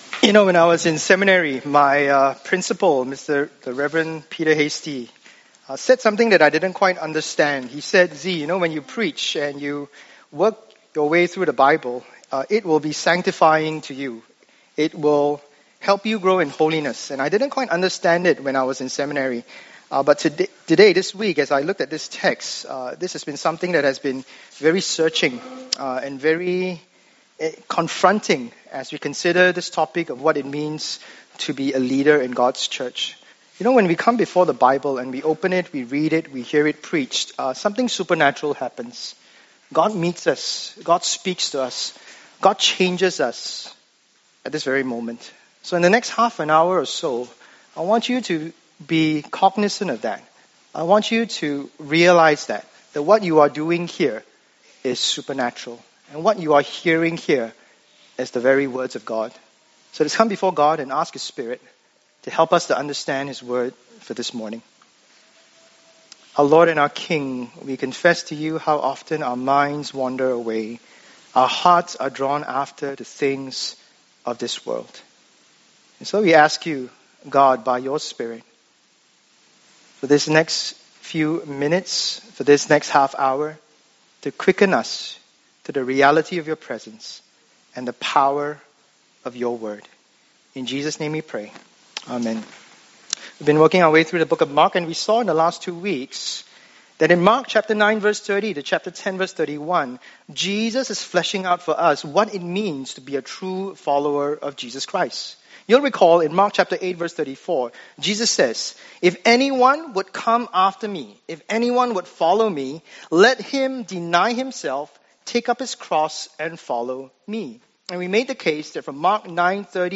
SERMONS | OCC v1